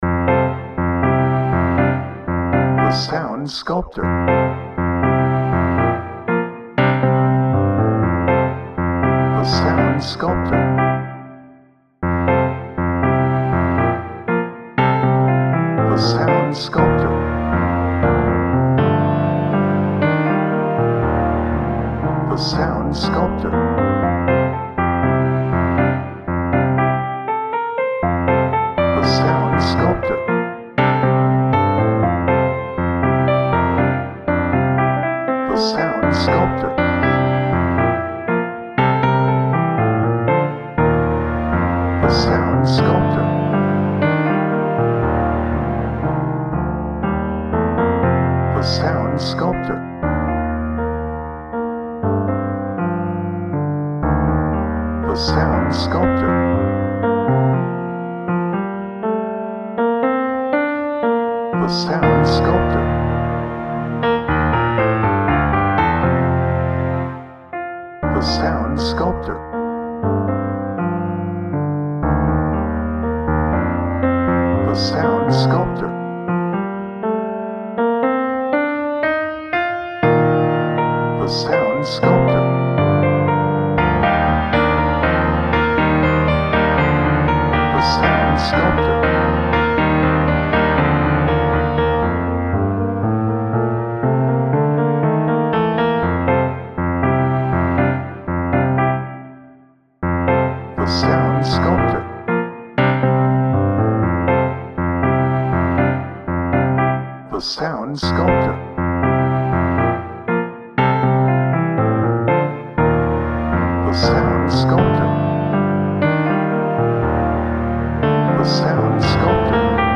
Bright
Happy
Positive